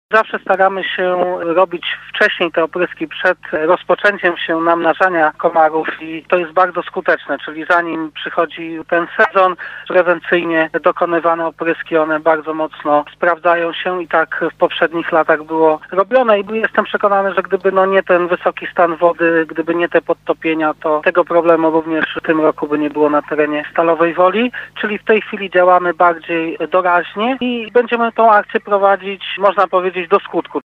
W efekcie miasto podobnie jak sąsiedzi musi walczyć z plagą tych owadów bardziej intensywnie niż w poprzednich latach. Mówił o tym prezydent Stalowej Woli Lucjusz Nadbereżny